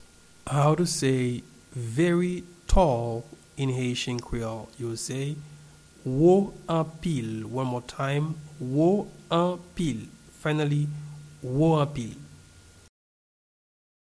Very-tall-in-Haitian-Creole-Wo-anpil-pronunciation.mp3